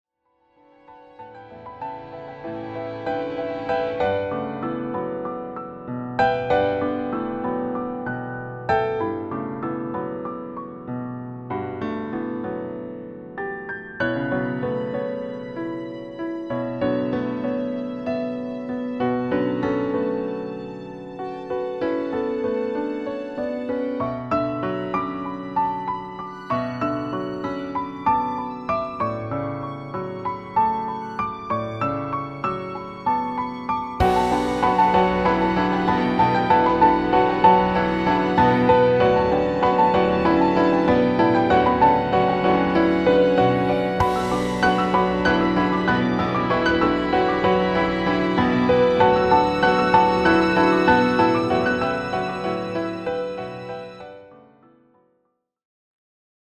Compositions